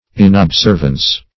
Inobservance \In`ob*serv"ance\, a. [L. inobservantia : cf. F.